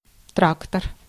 Ääntäminen
Synonyymit camion dix-roues Ääntäminen France: IPA: [tʁak.tœʁ] Haettu sana löytyi näillä lähdekielillä: ranska Käännös Ääninäyte Substantiivit 1. трактор {m} (traktor) Muut/tuntemattomat 2. тра́ктор {m} (tráktor) Suku: m .